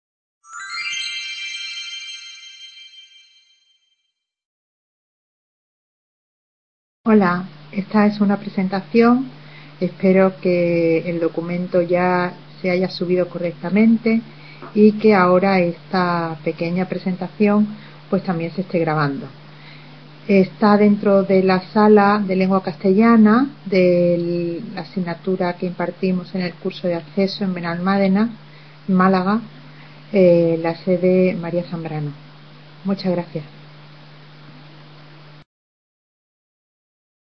Sala de Lengua Castellana en Benalmádena (Málaga)
Video Clase